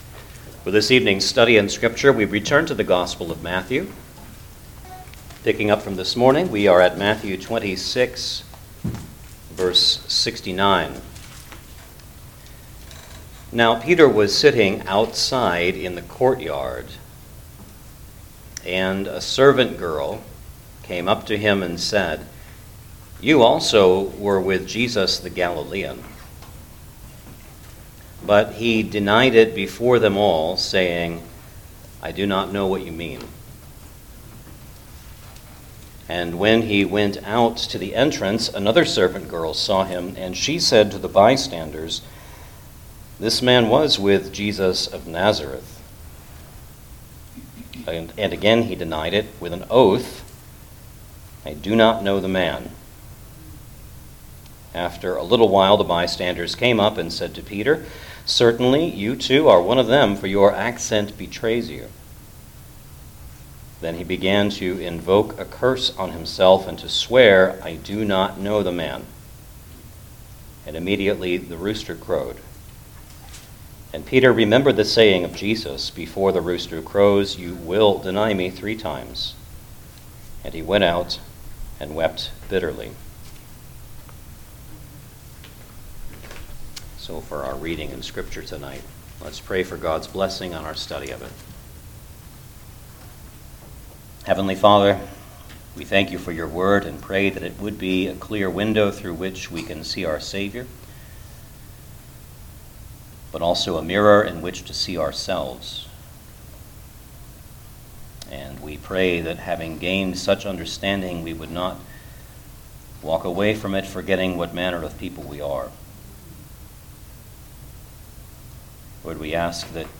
Gospel of Matthew Passage: Matthew 26:69-75 Service Type: Sunday Evening Service Download the order of worship here .